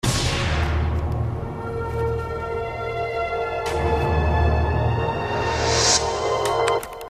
Ein Stück vom Anfang des Intros der 5. Staffel gibts auch.